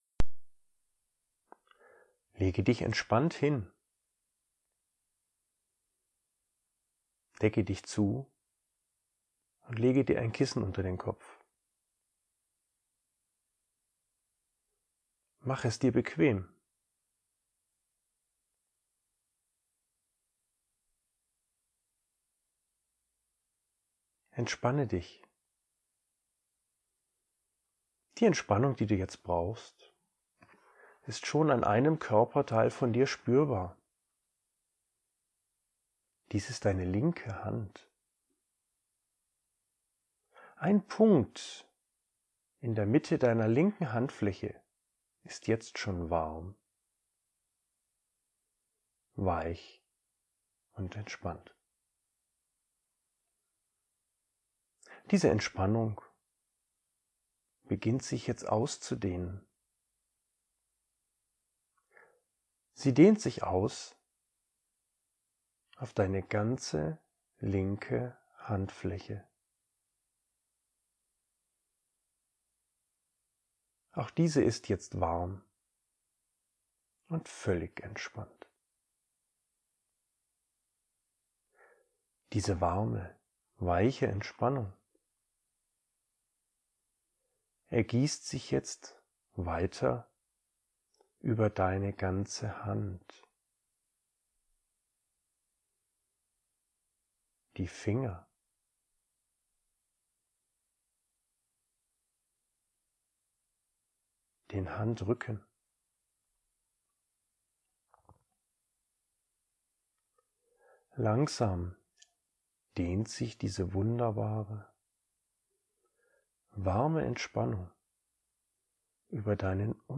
Video 1: Video 2: Video 3: Video 4: Video 5: Video 6: Die geführte Traumreise zur Angstlösung findest Du HIER.
04_Angstlösung1_ohneRauschen.mp3